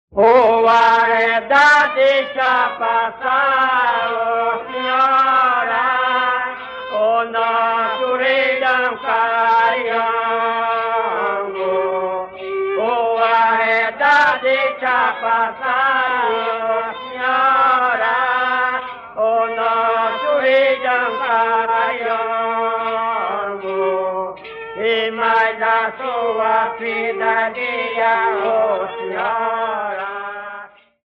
Na instrumentação destaca-se a percussão que estimula momentos de bailados e manobras vigorosas.
Congada
autor: Congos de Saiote, data: 1977